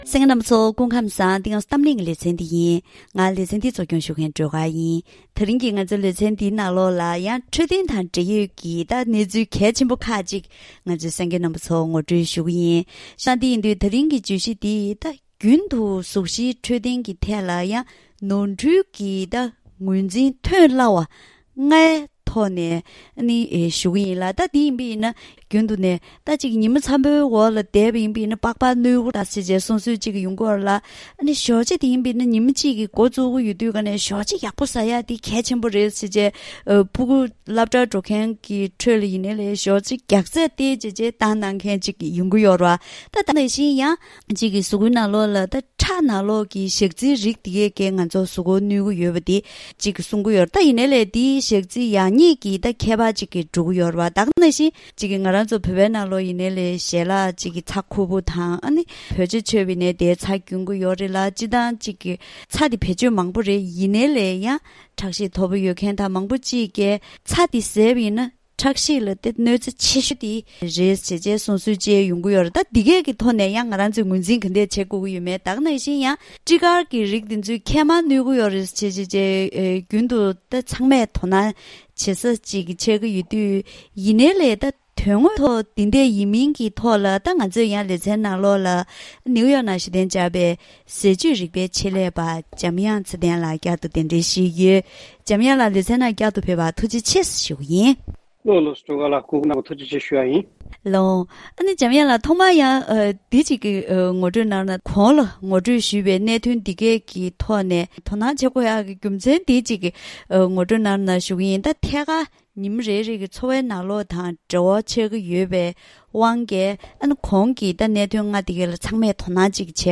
རྒྱུན་དུ་གཟུགས་གཞིའི་འཕྲོད་བསྟེན་ཐད་ལ་ནོར་འཁྲུལ་གྱི་ངོས་འཛིན་ཐོན་སླ་བ་ལྔ་ཡི་ཐོག་ལ་ཟས་བཅུད་རིག་པའི་ཆེད་ལས་པར་བཀའ་འདྲི་ཞུས་པ།